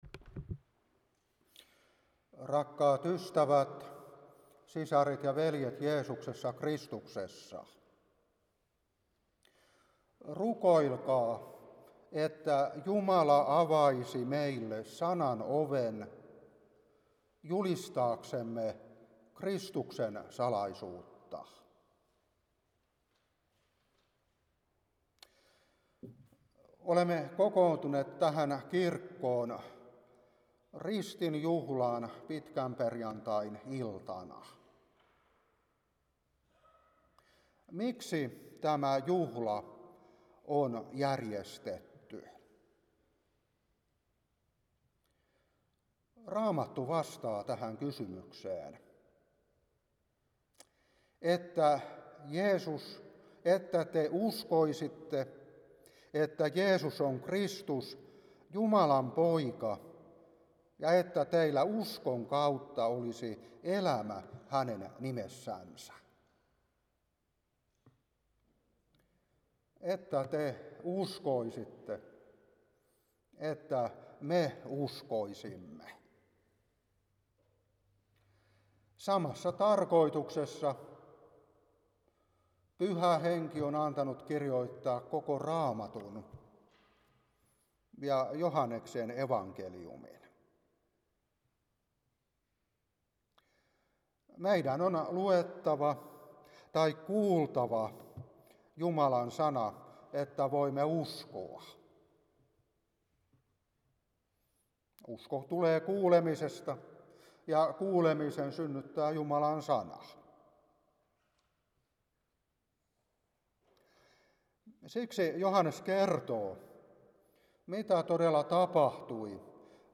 Ristin saarna
2024-3_ristin_saarna.mp3